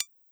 GenericButton12.wav